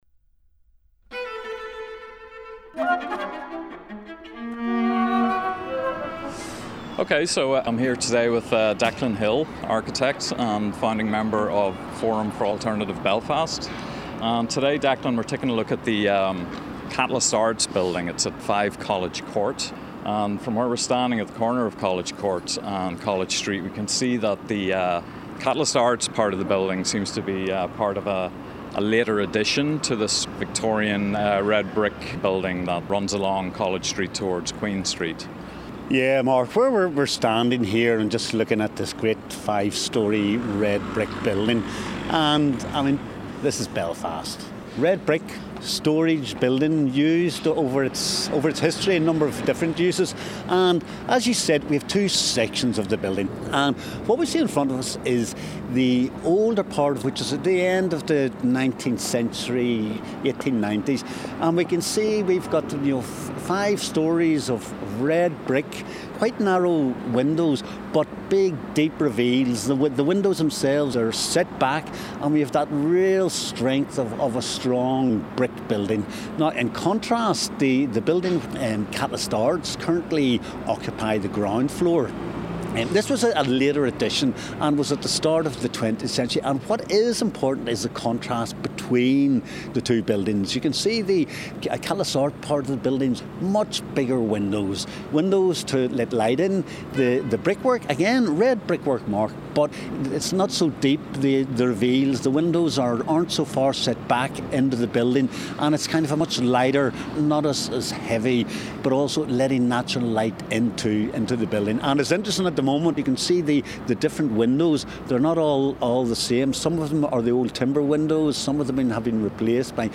Bel Edifice was made for 'A False Sense', a group exhibition at Catalyst Arts, Belfast that set out to explore ideas of expectation and encounter around the experience of visiting a gallery—and the use of the peripheries of the gallery space as a site for making work.
bel-edifice-excerpt.mp3